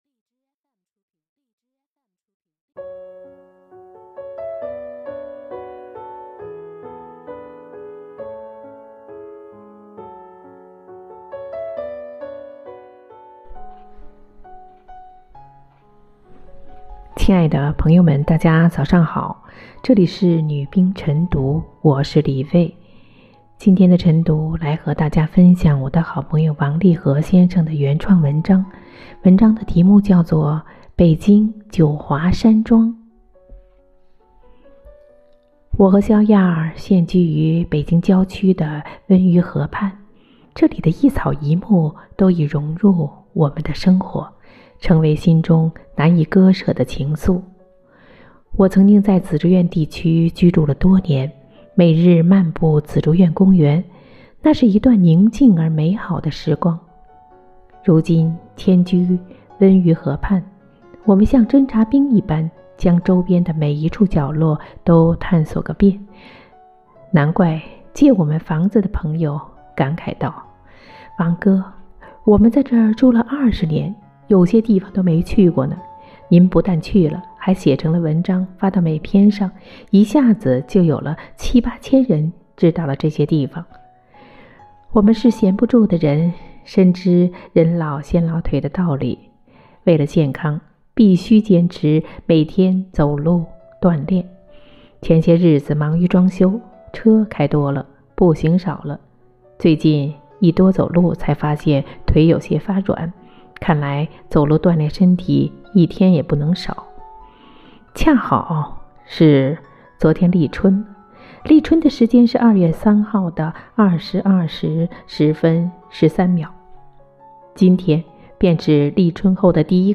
每日《女兵诵读》北京九华山庄